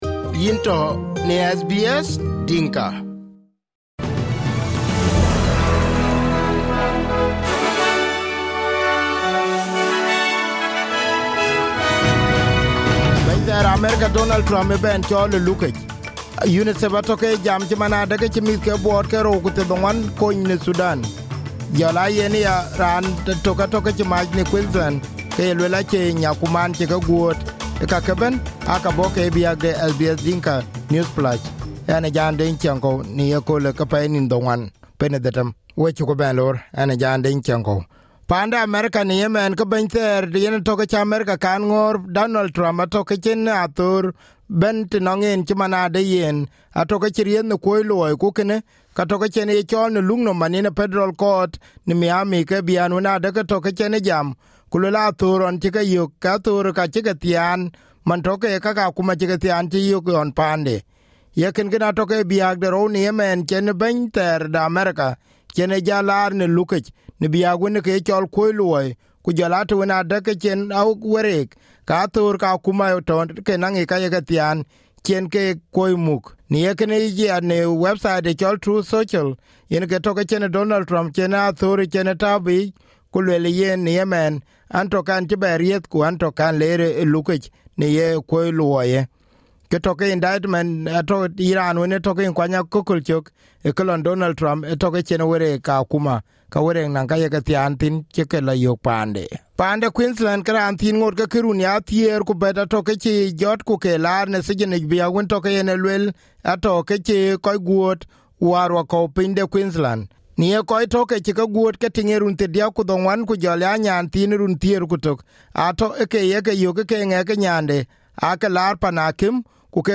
SBS Dinka News Flash 09/06/2023